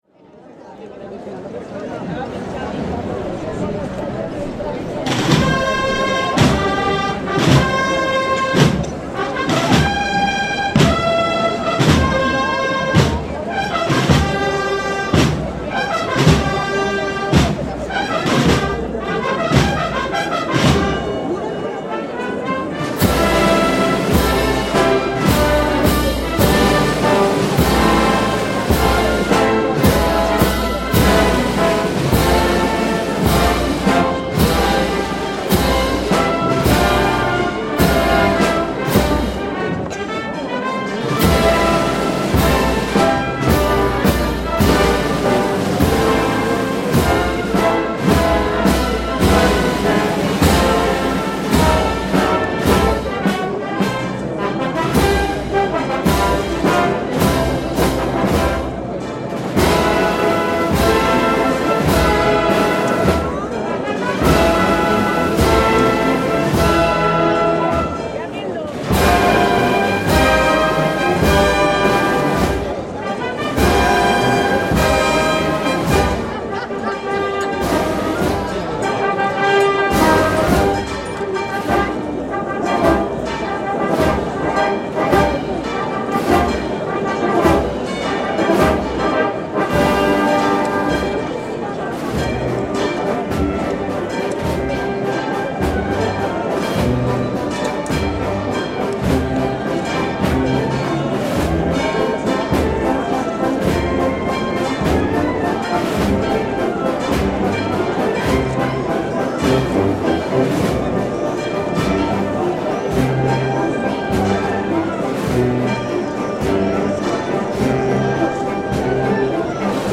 Solemne procesión de CORPUS CHRISTI en la ciudad de Valladolid (España).
Es imprescindible visionar las fotografías con el ambiente sonoro de la procesión,